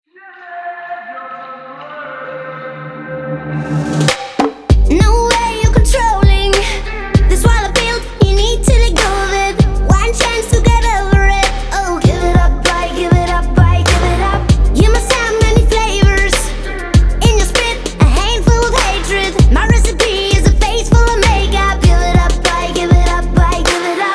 • Качество: 320, Stereo
поп
dance
забавный голос
детский голос